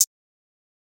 Closed Hats
HiHat (10).wav